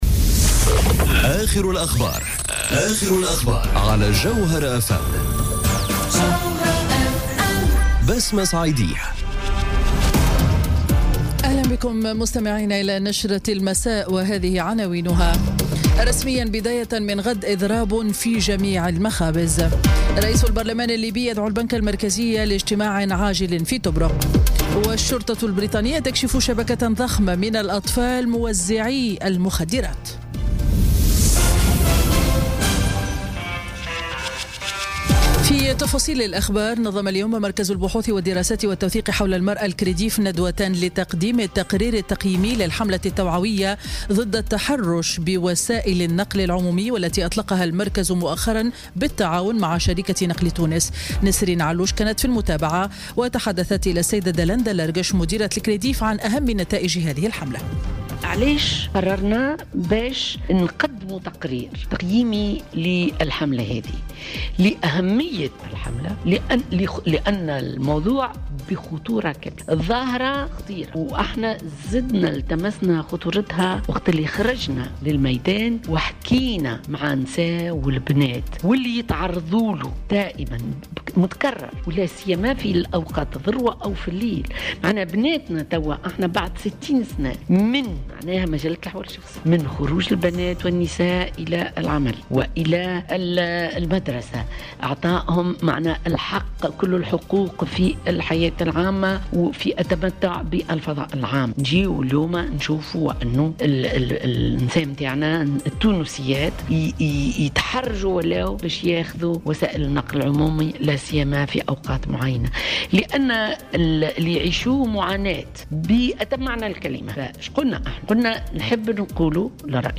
نشرة أخبار السابعة مساء ليوم الثلاثاء 28 نوفمبر 2017